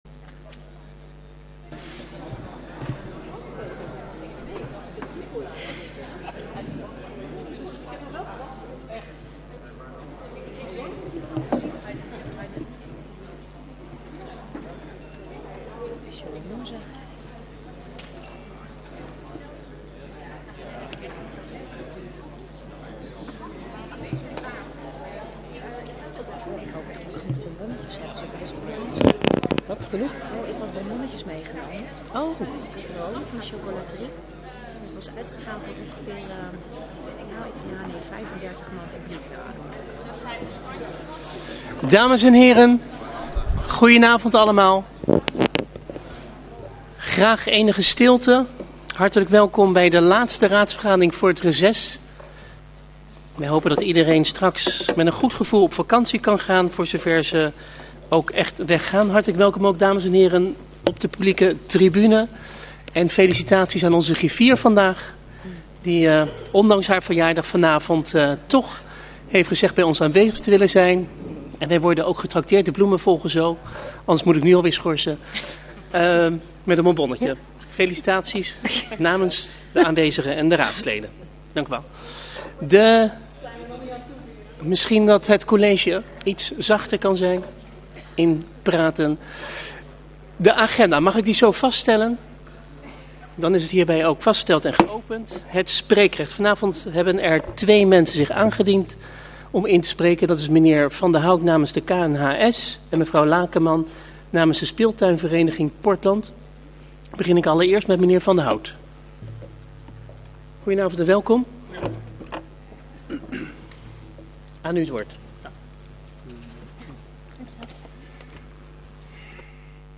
Raadsvergadering
Locatie: Raadzaal